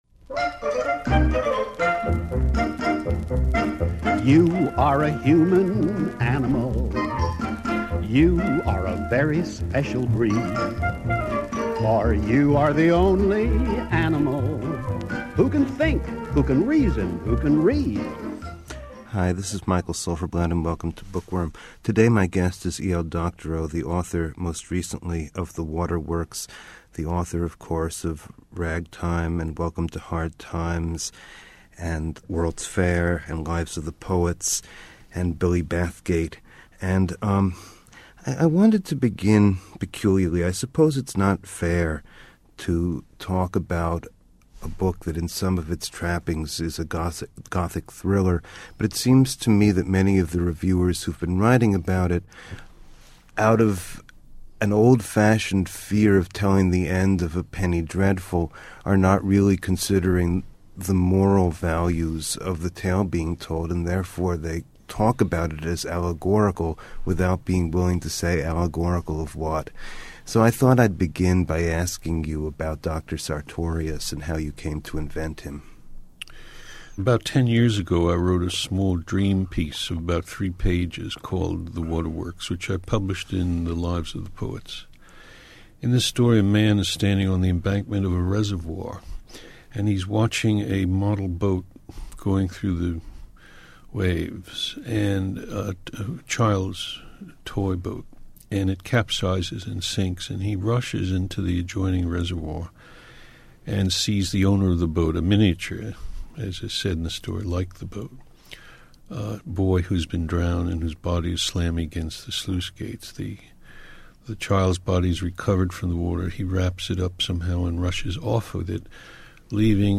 The Waterworks A discussion of the morality of mad scientists, New York history, and the evolution of the plot of E.L. Doctorow's best-selling novel.